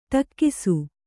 ♪ ṭakkisu